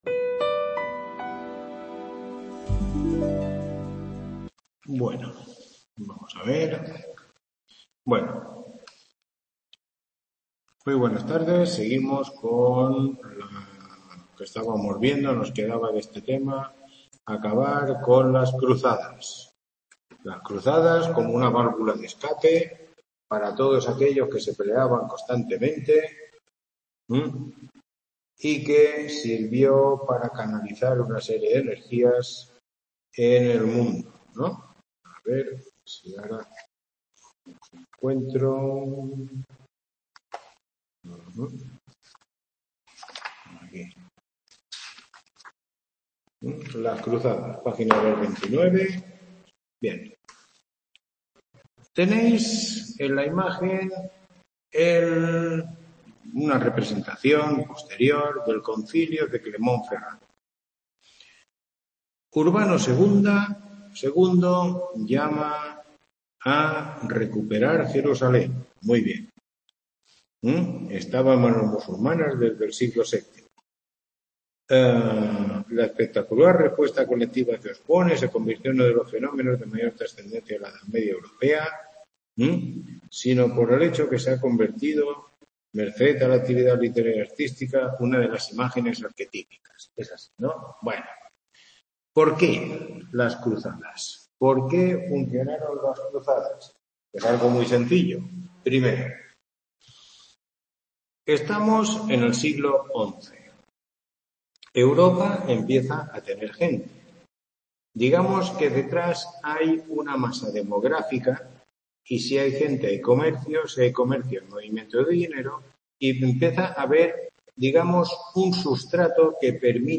Tutoría 9 (comentario texto)